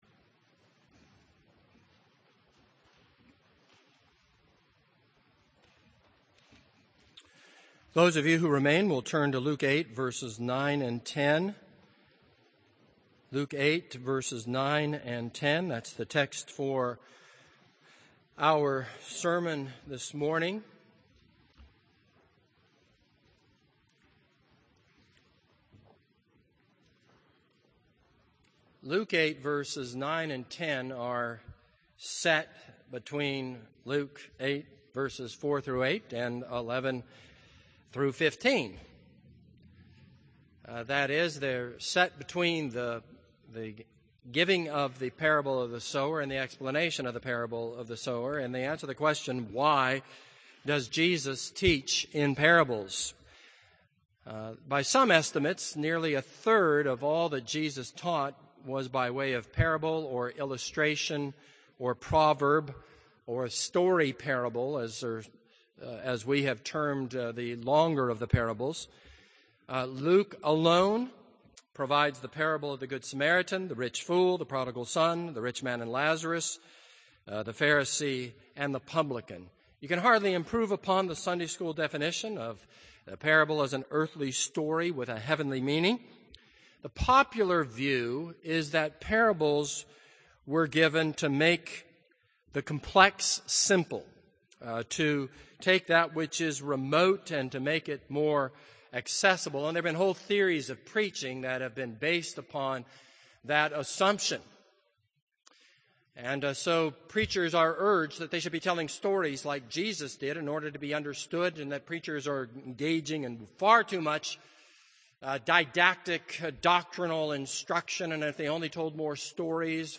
This is a sermon on Luke 8:9-10.